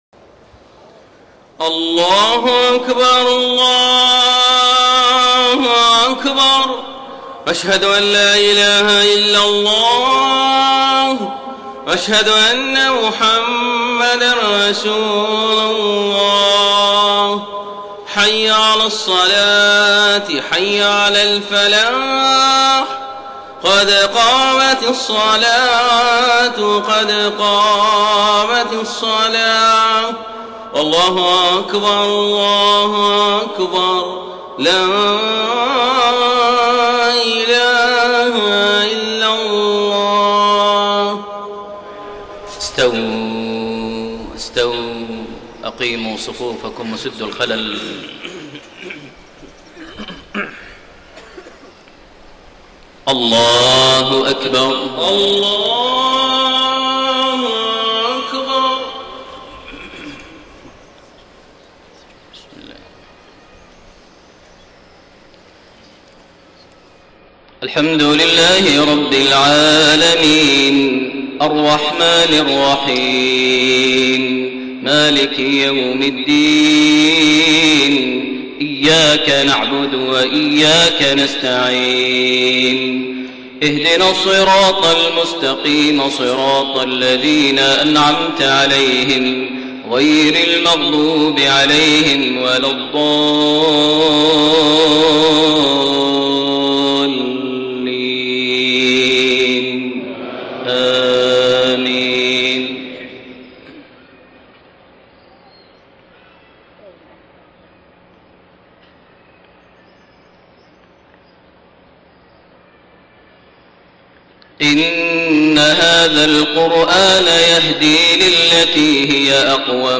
Maghrib prayer from Surah Al-Israa > 1433 H > Prayers - Maher Almuaiqly Recitations